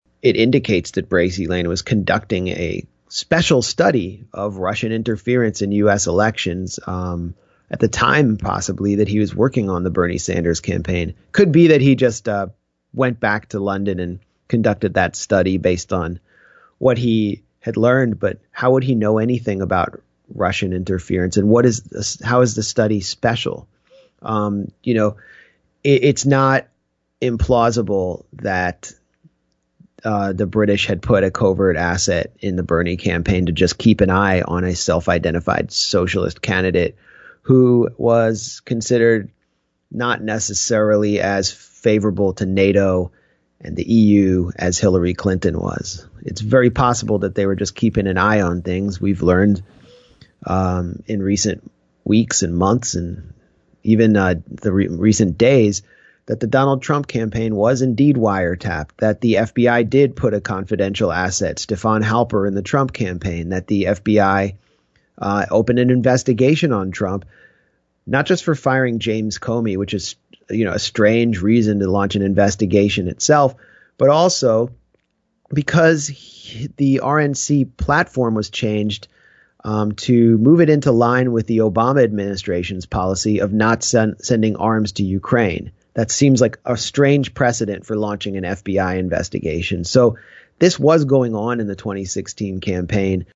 In-Depth Interview: Reporter Max Blumenthal Exposes Britain’s “Integrity Initiative” and its US Expansion Efforts